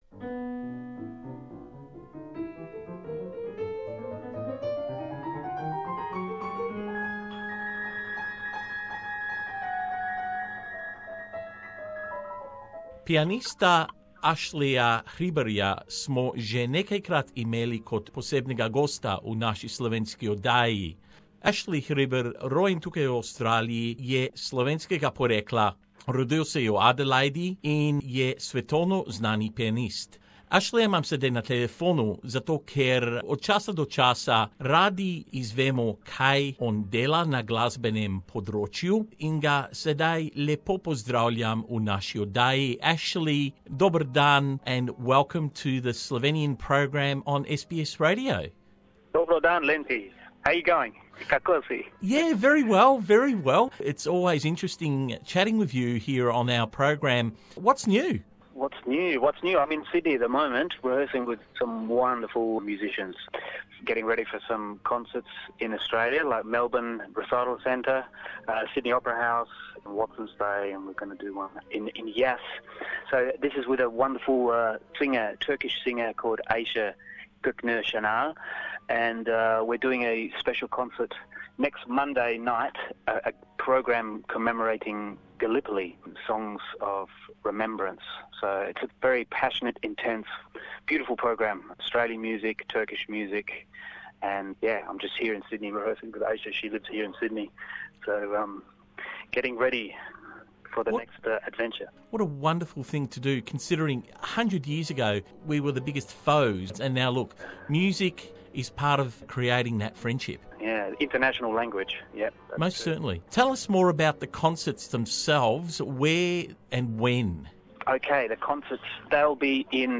He joins us for a friendly chat to promote his latest concert and recital schedule in Australia.